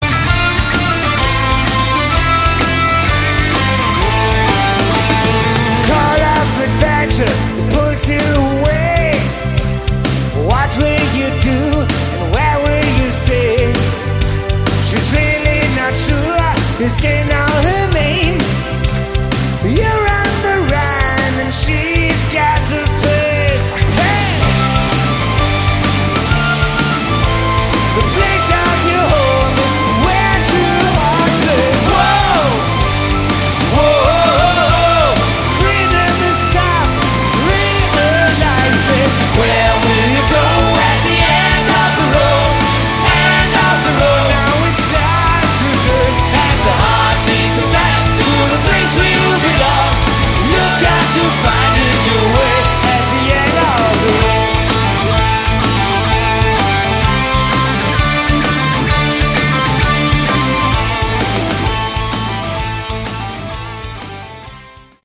Category: Hard Rock
lead vocals, guitar
bass, vocals
keyboards, vocals
drums